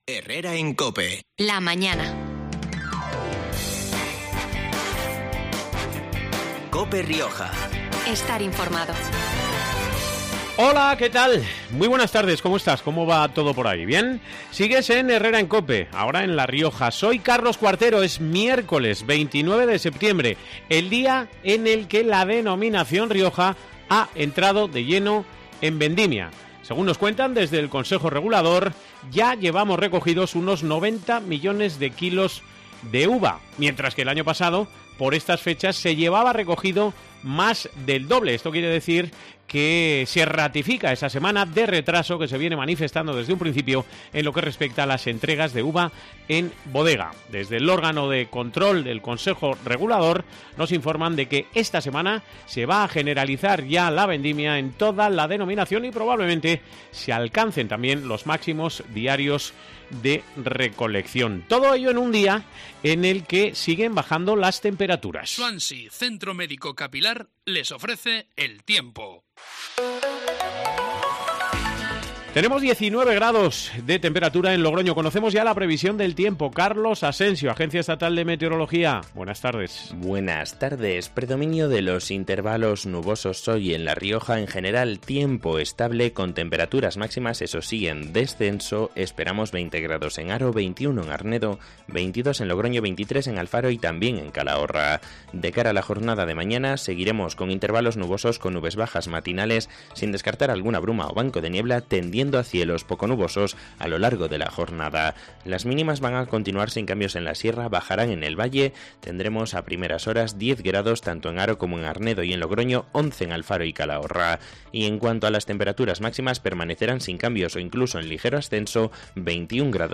De ello hemos hablado este miércoles en COPE Rioja